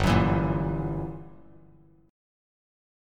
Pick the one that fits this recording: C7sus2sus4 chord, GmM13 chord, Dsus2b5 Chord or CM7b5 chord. GmM13 chord